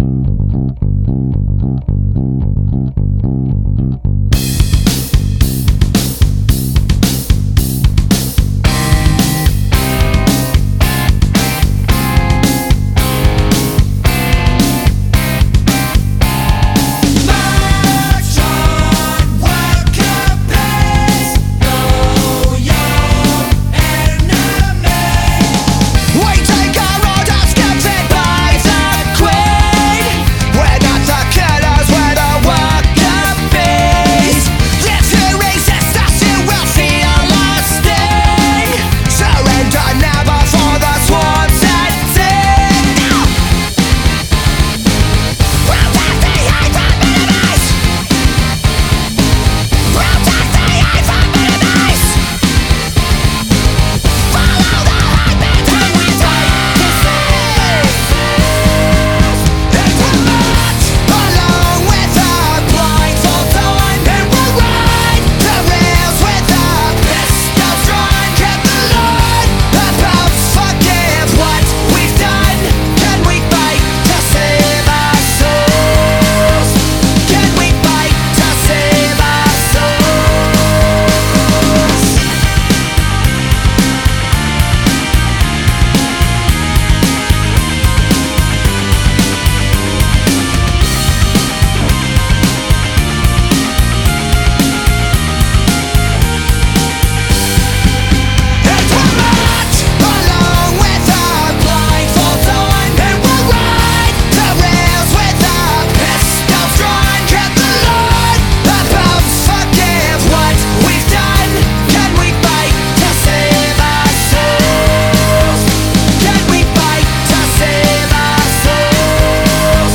BPM111
MP3 QualityMusic Cut